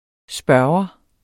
Udtale [ ˈsbɶɐ̯wʌ ]